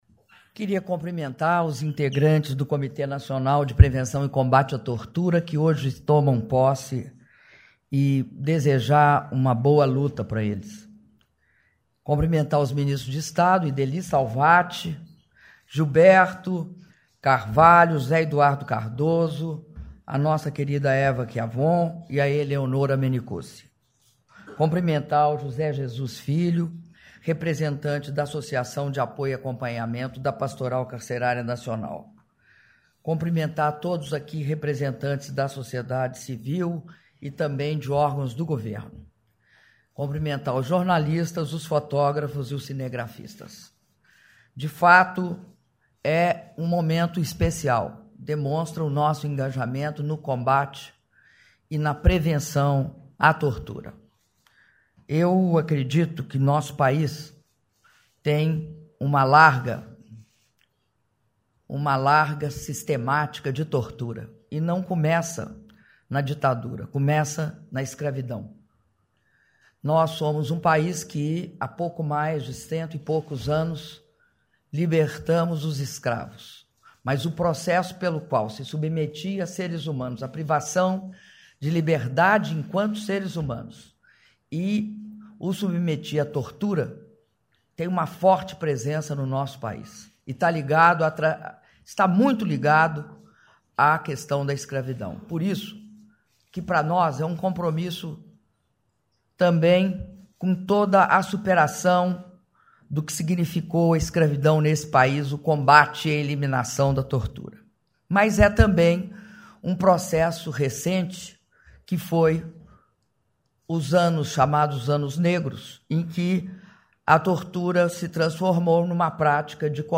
Áudio do discurso da Presidenta da República, Dilma Rousseff, na posse dos membros do Comitê Nacional de Prevenção e Combate à Tortura - Brasília/DF (05min10s)